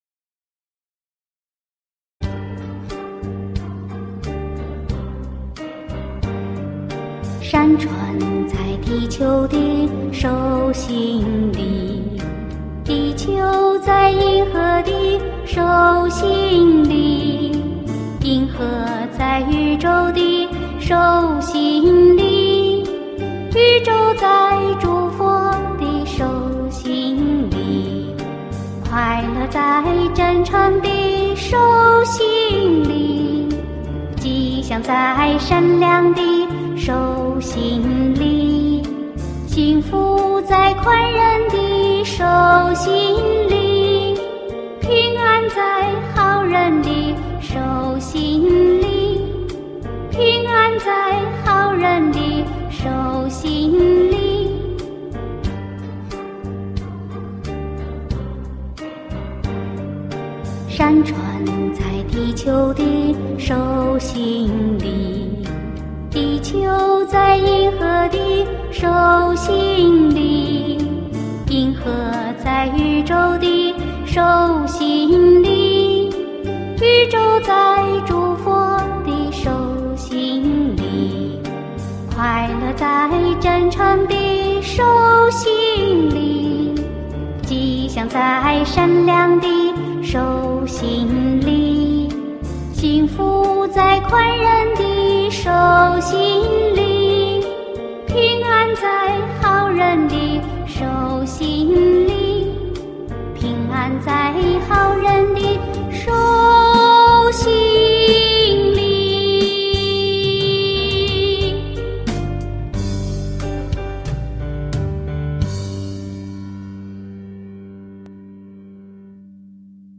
儿歌：手心